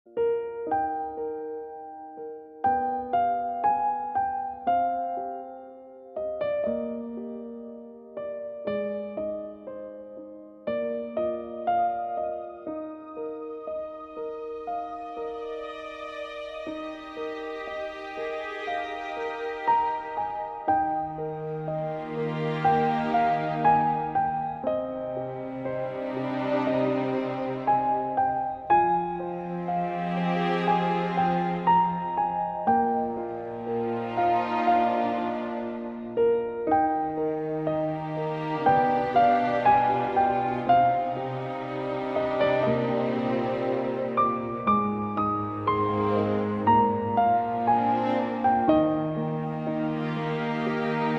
分类: MP3铃声